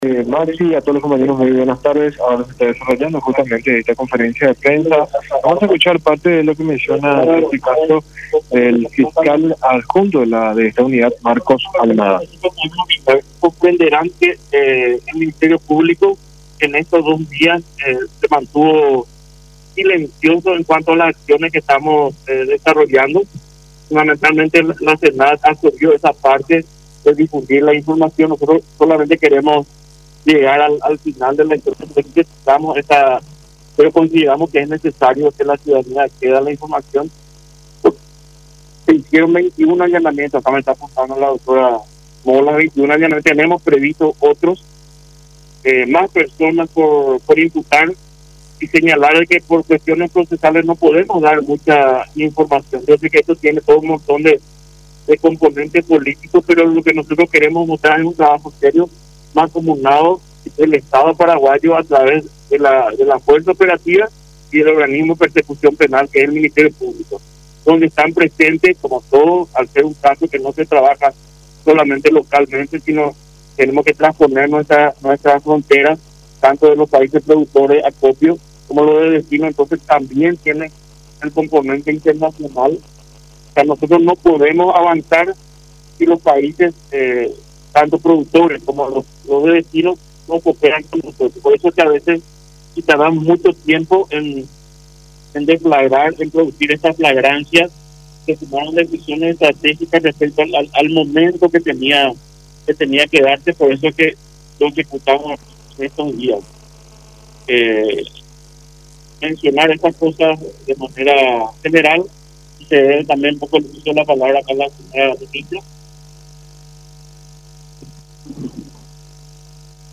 “El Ministerio Público se manifestó silencioso en estos días que estamos desarrollando los procedimientos. La SENAD se asoció a esta parte. Ahora solamente queremos llegar al final de la investigación”, dijo el fiscal Marcos Almada, uno de los intervinientes, ante los medios de prensa, afirmando que hasta el momento se han realizado 21 allanamientos y que “se tienen previstos otros más”.